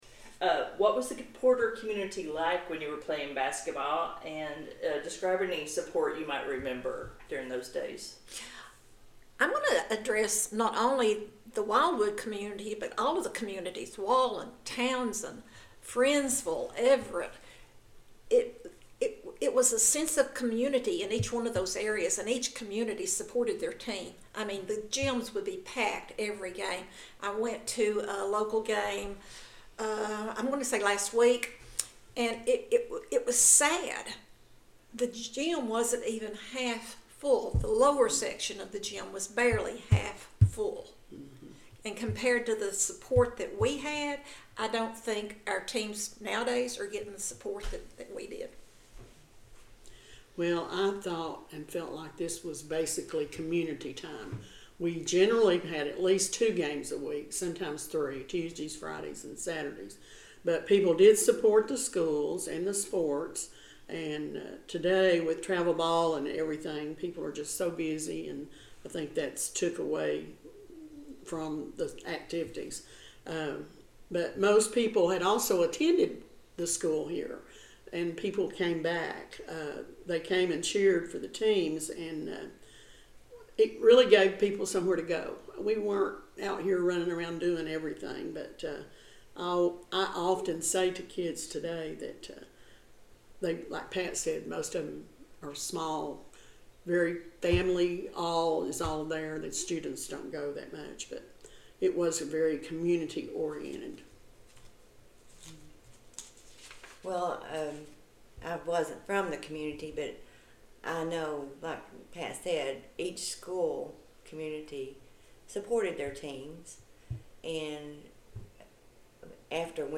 An Audio Interview